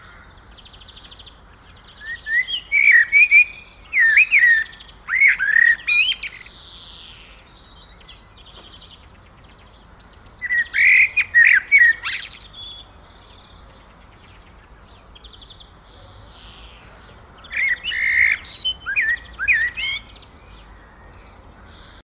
Ringetone Amselgesang
Kategori Dyr
Amselgesang.mp3